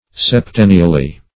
septennially - definition of septennially - synonyms, pronunciation, spelling from Free Dictionary Search Result for " septennially" : The Collaborative International Dictionary of English v.0.48: Septennially \Sep*ten"ni*al*ly\, adv. Once in seven years.